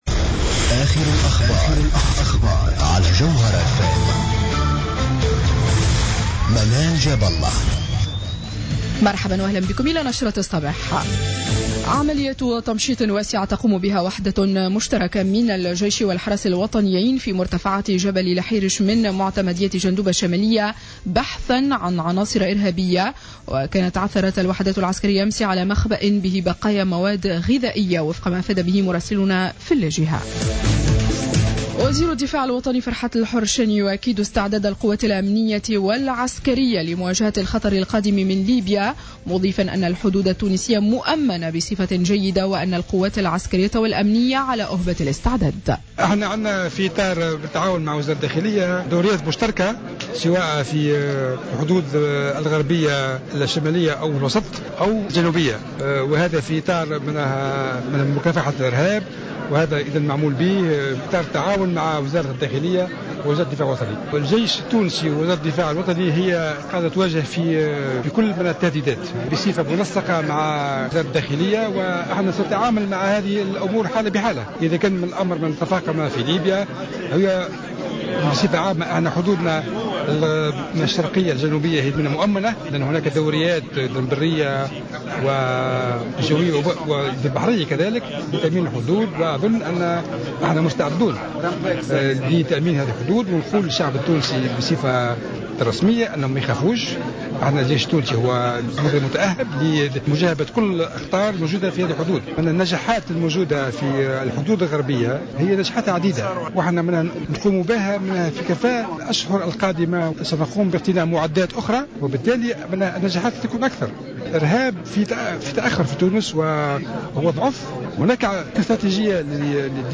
نشرة أخبار السابعة صباحا ليوم السبت 21 فيفري 2015